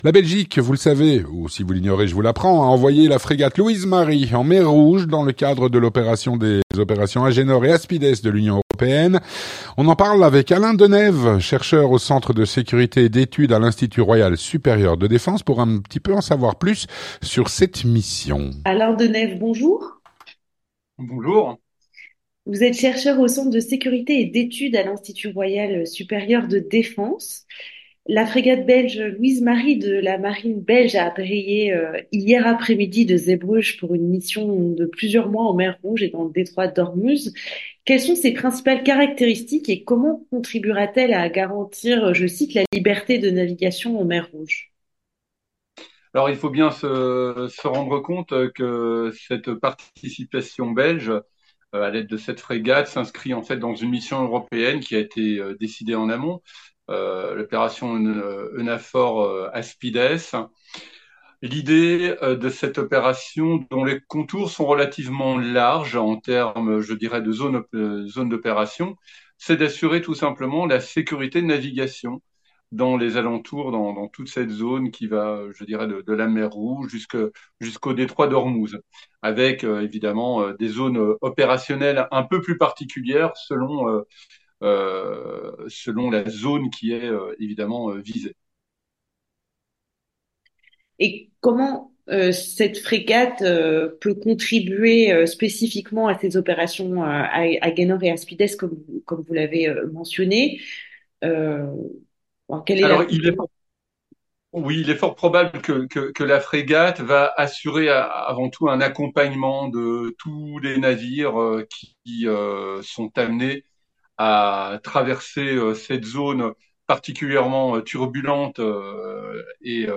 L'entretien du 18H - La Belgique a envoyé la frégate Louise-Marie en Mer Rouge.